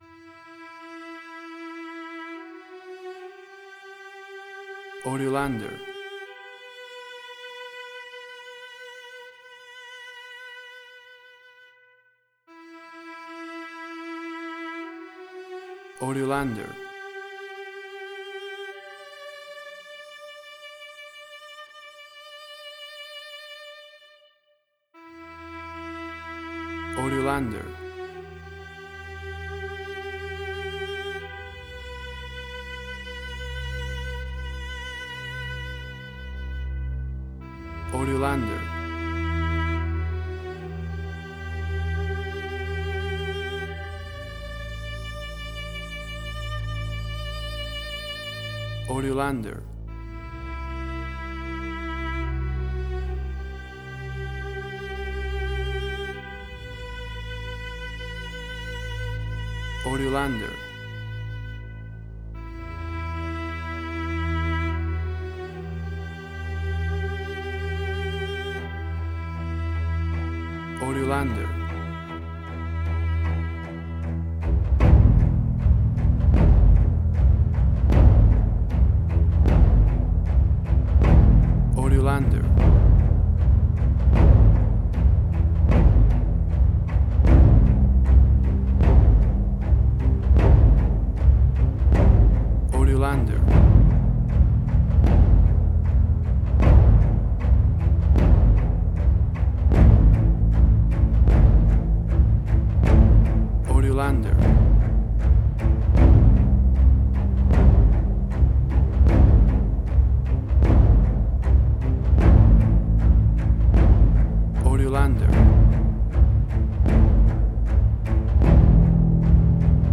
Suspense, Drama, Quirky, Emotional.
Tempo (BPM): 77